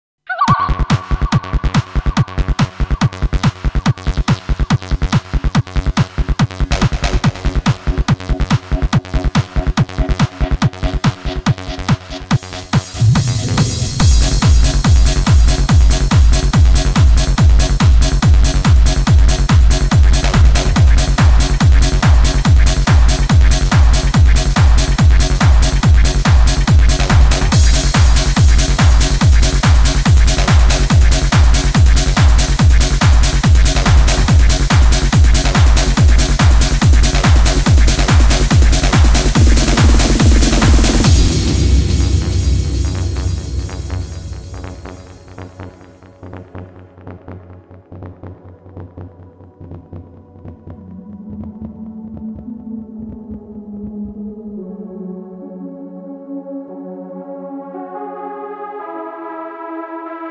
Электронная